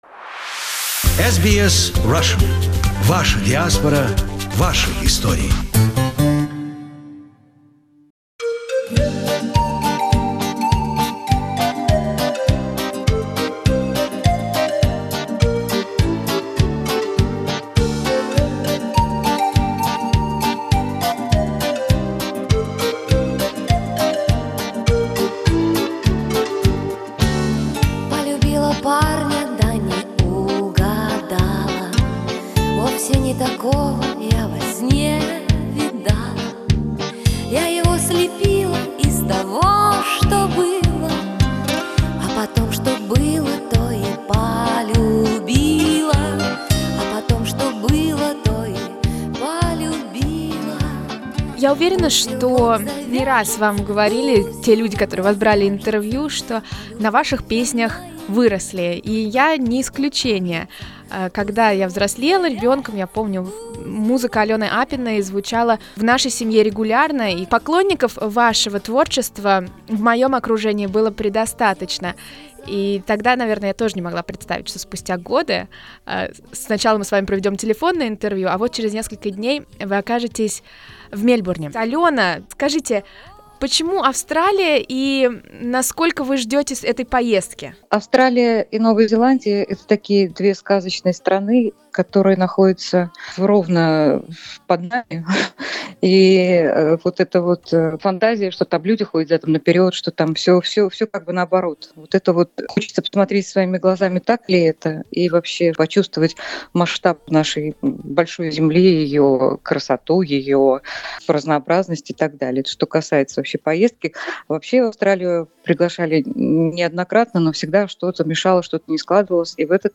We speak with Alena before she embarks on her journey about her career, views on contemporary music and way to look at politics.